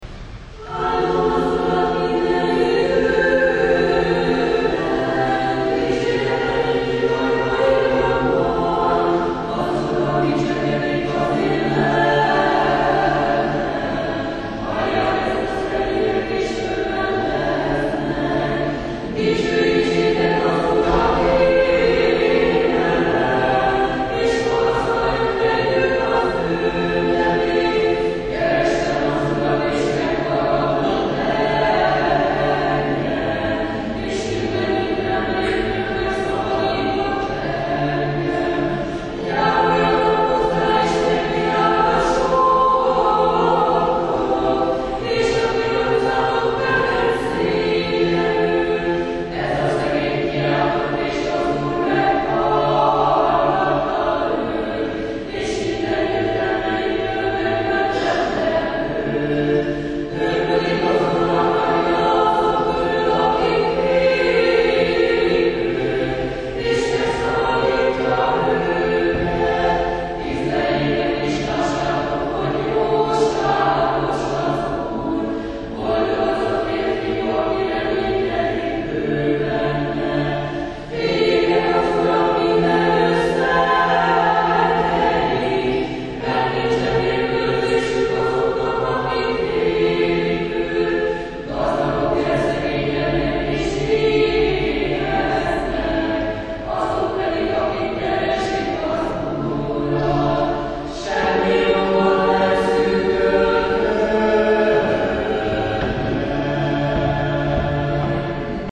A csernyigovi akadémiai kórus koncertje a Nagyboldogasszony székesegyházban
A templomot soknemzetiségű hallgatóság töltötte meg.
Az esti istentisztelet hangfelvételének egyes részei az alább felsorolt énekek címeire kattintva hallgathatók meg.